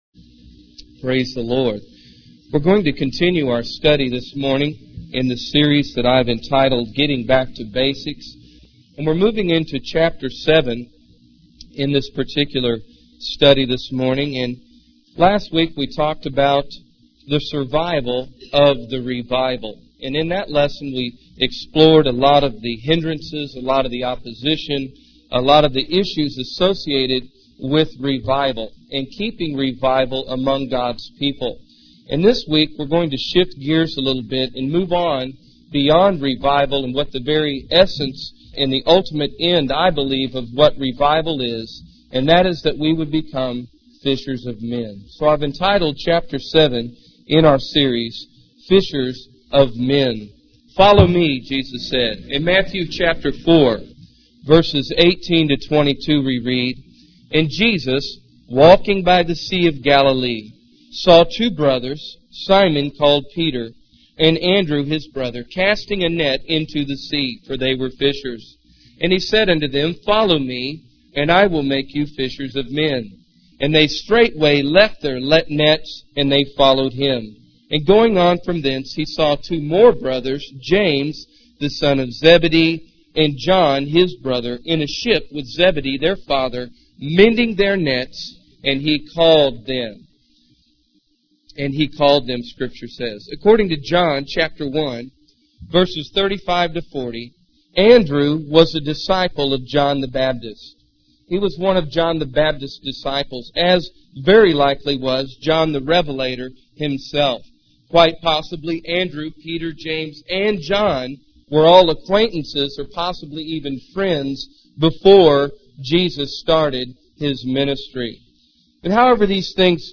In this sermon, the preacher continues the series on getting back to basics and focuses on the concept of becoming fishers of men. He references the story of Jesus calling Peter and Andrew to follow Him and become fishers of men. The preacher emphasizes the simplicity of the gospel message and the importance of counting the cost of following Jesus.